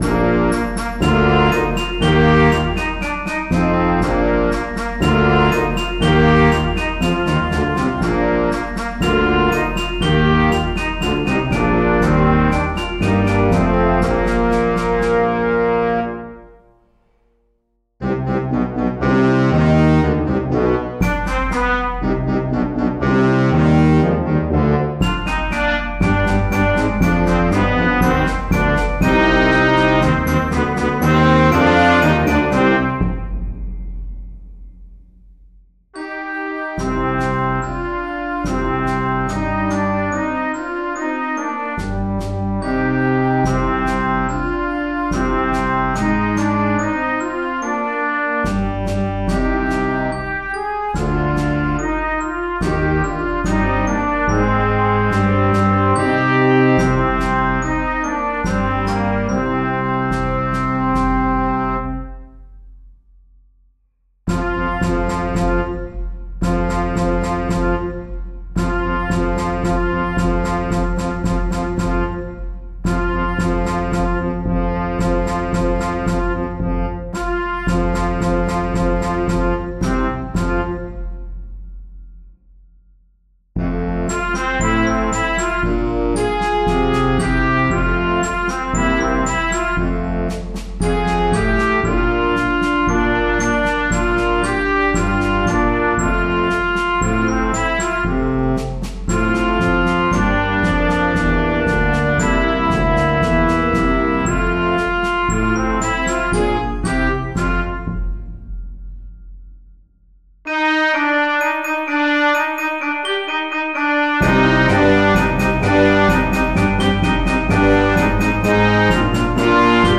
Clarinet Quartet